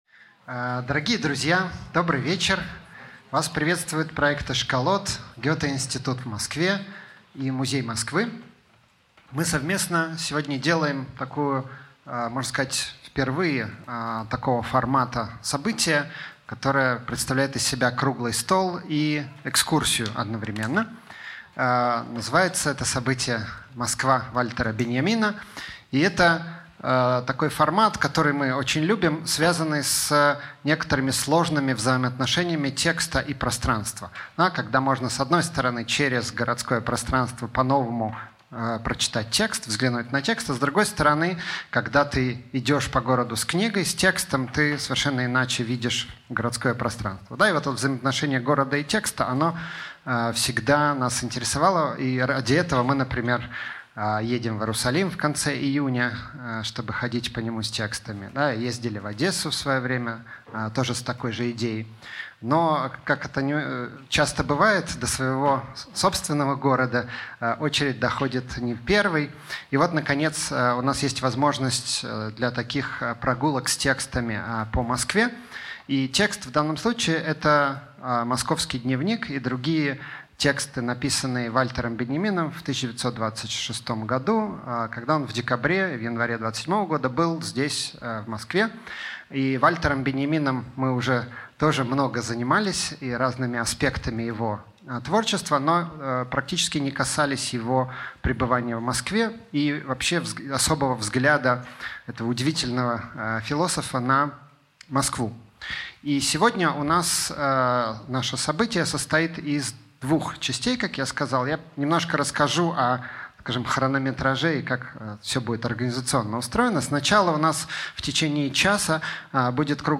Аудиокнига Москва Вальтера Беньямина | Библиотека аудиокниг